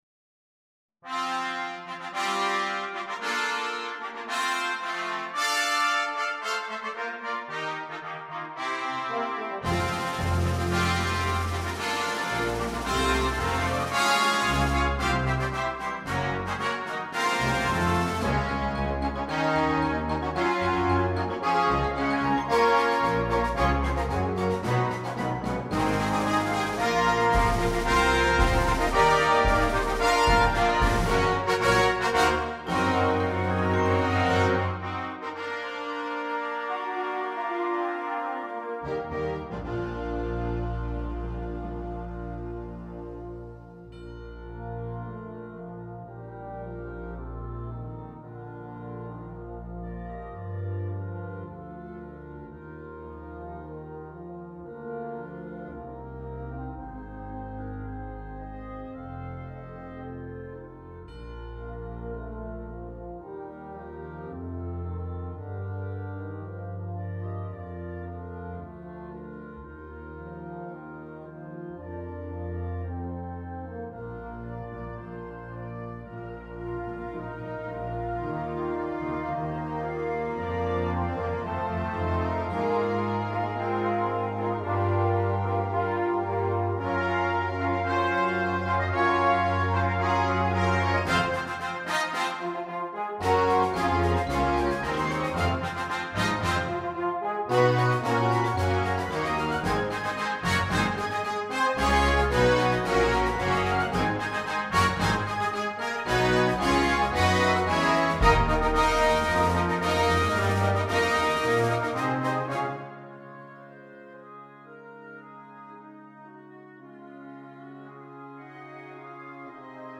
Gattung: Fanfare
Besetzung: Blasorchester